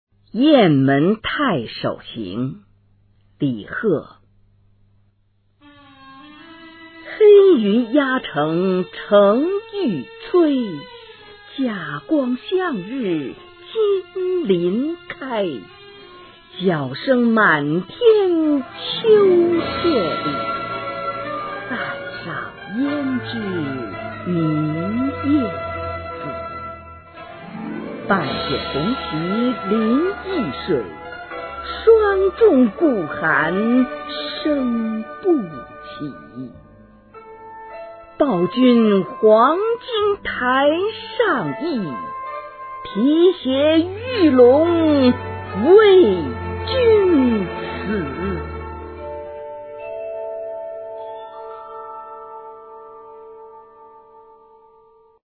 《雁门太守行》原文和译文（含赏析、Mp3朗读）　/ 李贺